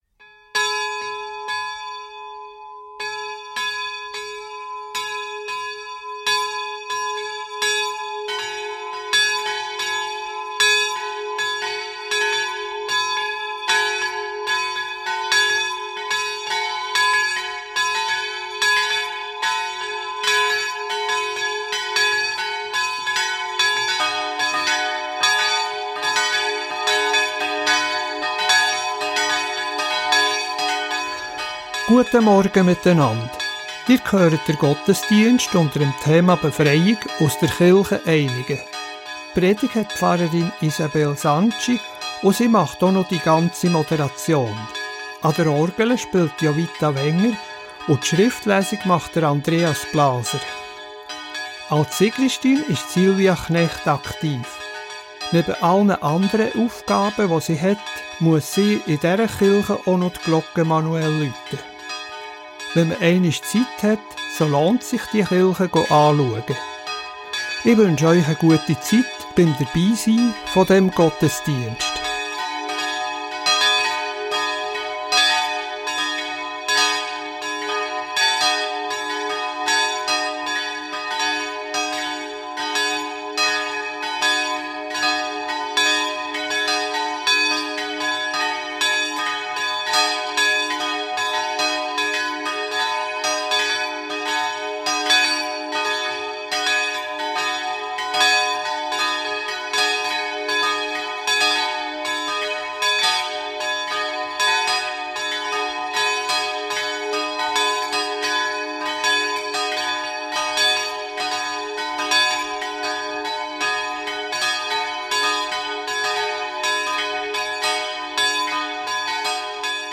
Reformierte Kirche Einigen ~ Gottesdienst auf Radio BeO Podcast